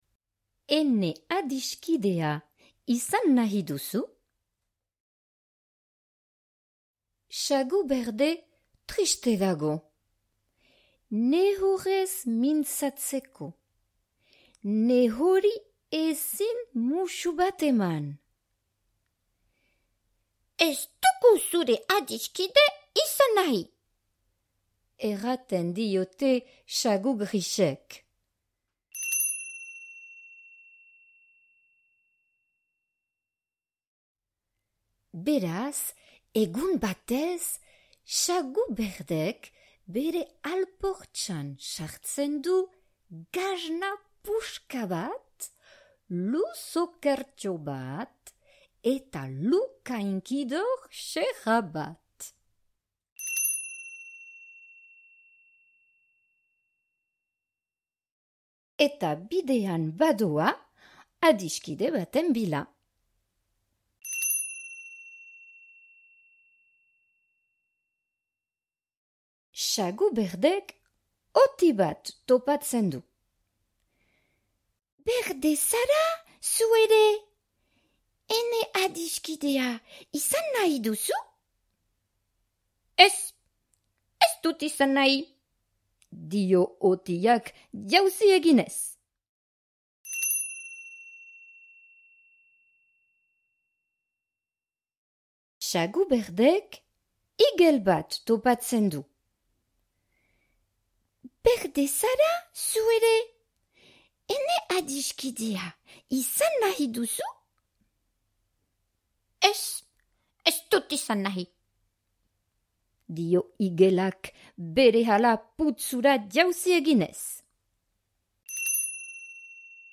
Ene adiskidea izan nahi duzu? - batuaz - ipuina entzungai